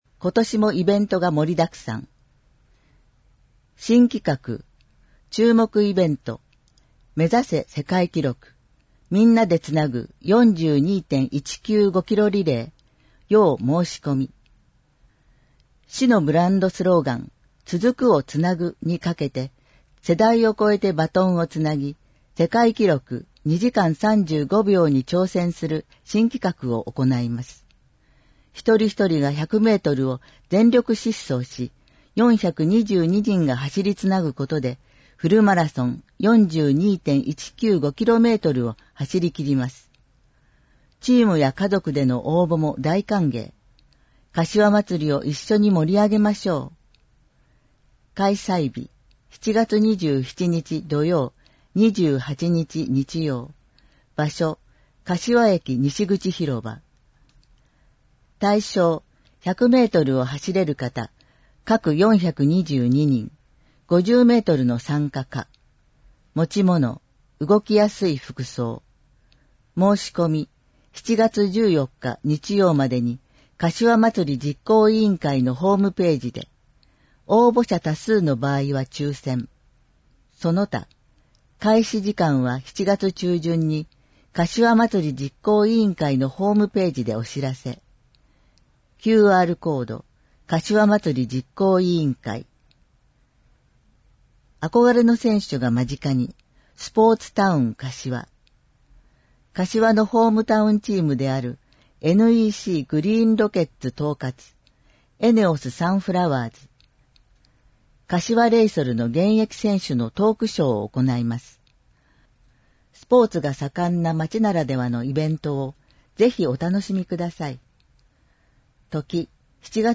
• 広報かしわの内容を音声で収録した「広報かしわ音訳版」を発行しています。
• 発行は、柏市朗読奉仕サークルにご協力いただき、毎号行っています。